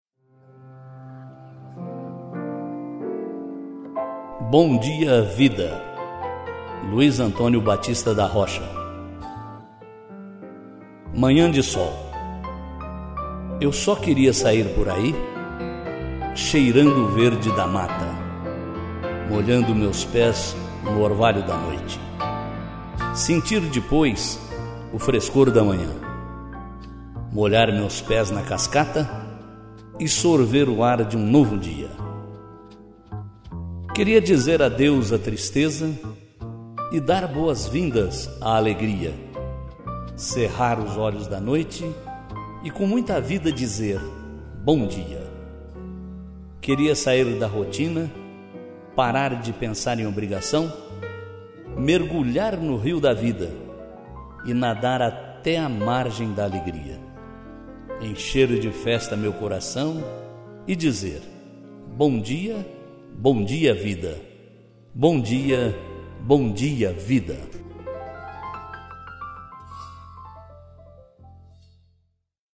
Bom dia Vida - locução